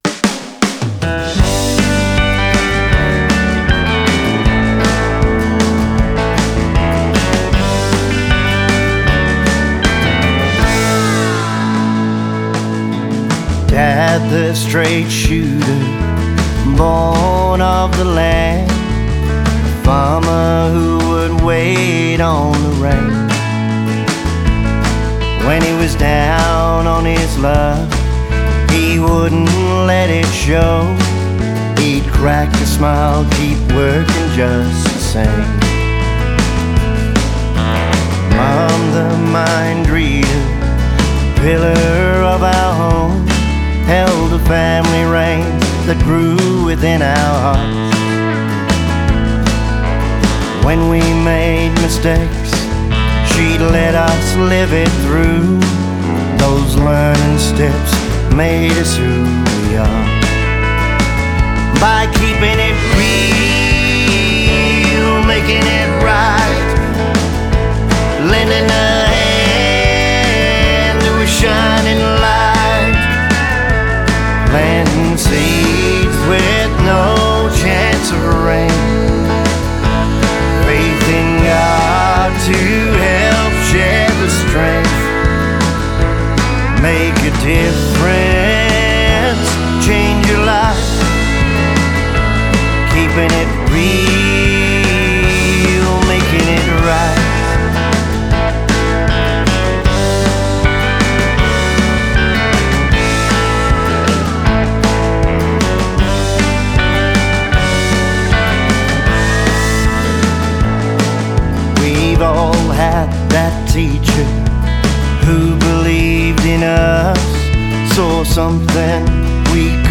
a straight ahead shuffle feel song